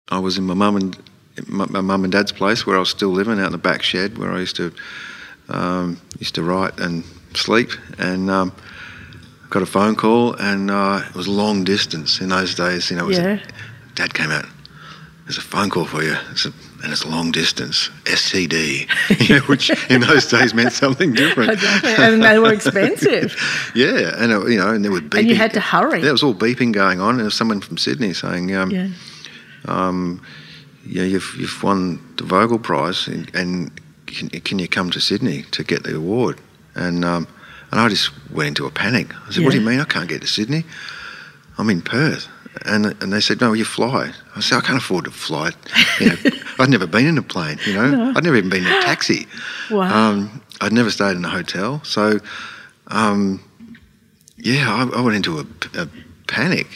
The feedback we receive is that the interviews are genuine and conversational.
And most recently Tim Winton, whose candor and openness about his life made for a terrific conversation—I was besotted by him!